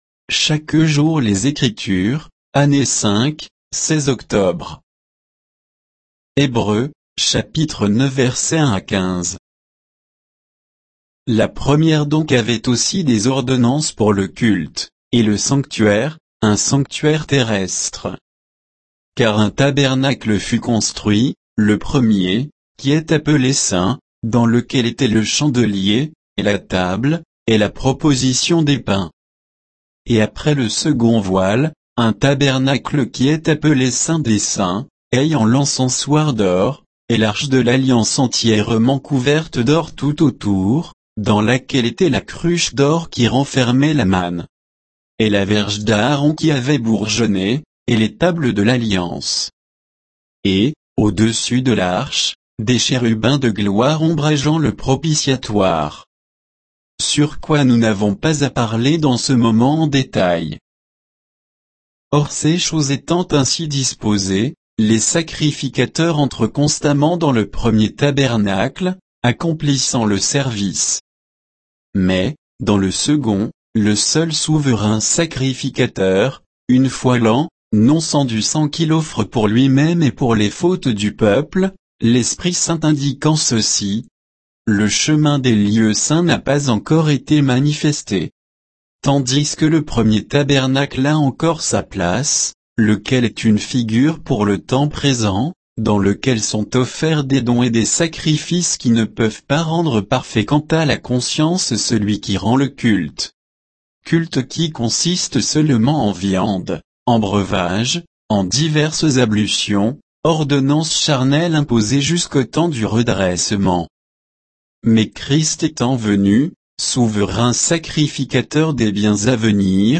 Méditation quoditienne de Chaque jour les Écritures sur Hébreux 9, 1 à 15